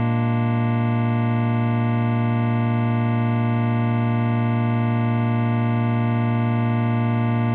bb7-chord.ogg